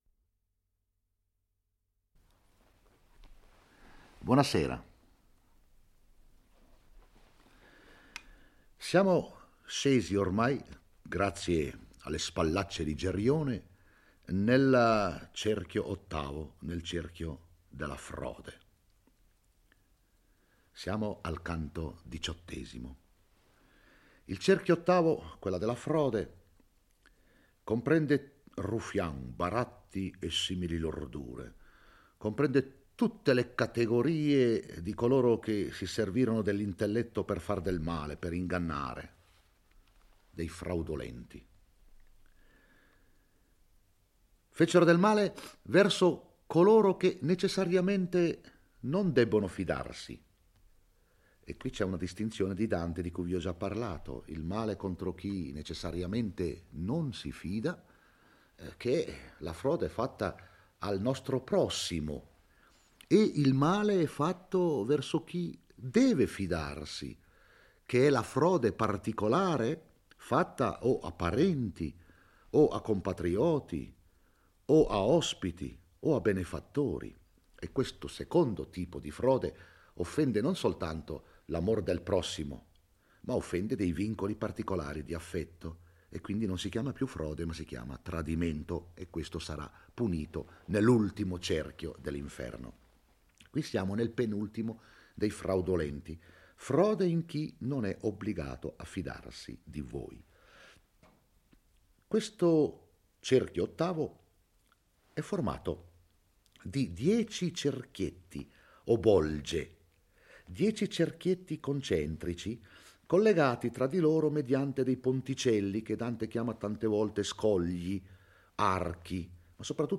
legge e commenta il XVIII canto dell'Inferno. Dante e Virgilio giungono nell'ottavo cerchio, luogo in cui sono rinchiusi, divisi in due bolge, i ruffiani/seduttori e gli adulatori. I primi sono distinti in due schiere che camminano in senso contrario di marcia e vengono frustati con violenza dai diavoli.